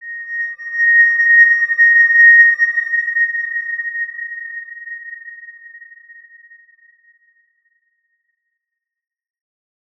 X_Windwistle-A#5-mf.wav